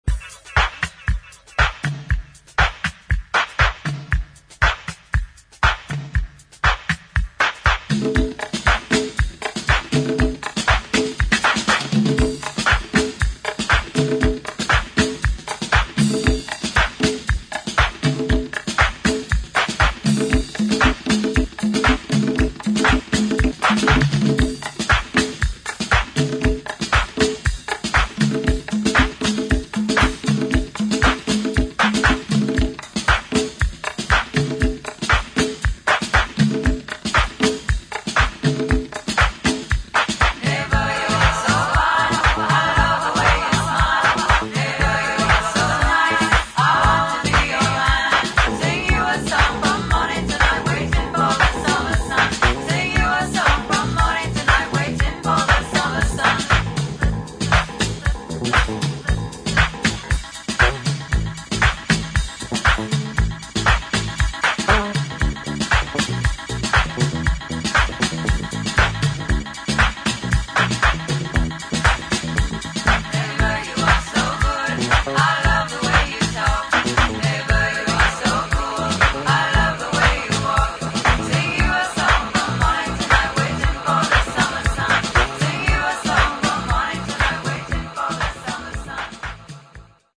[ DEEP HOUSE / DISCO ]
Dub Version